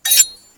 Other Sound Effects
sword.4.ogg